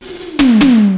FILLTOMEL1-R.wav